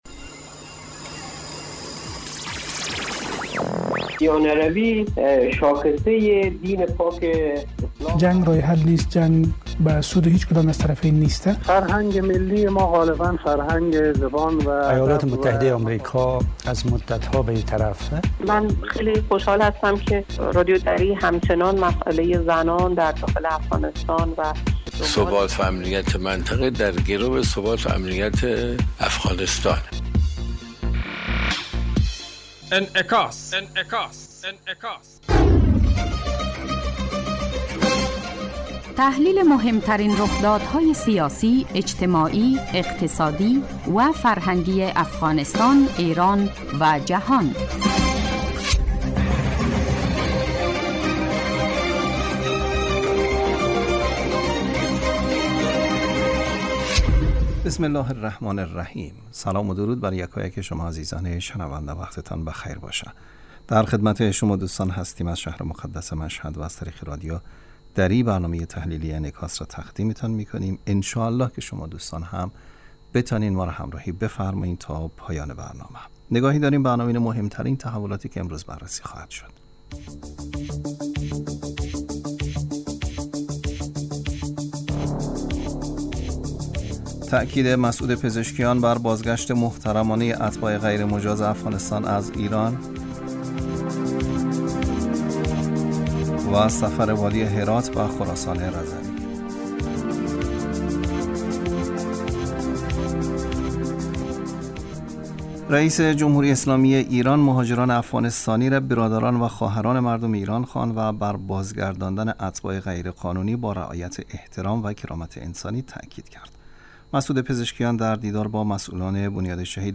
برنامه انعکاس به مدت 30 دقیقه هر روز در ساعت 12:30 ظهر (به وقت افغانستان) بصورت زنده پخش می شود.